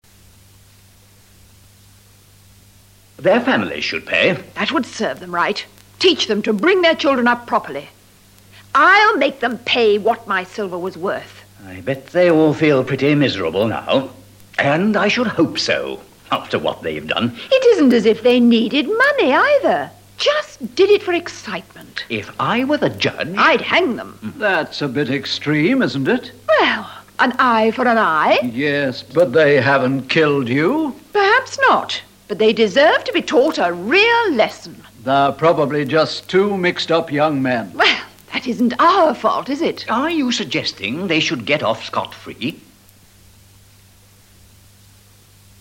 Harry has just heard from the police about their robbery. This dialogue expresses revenge.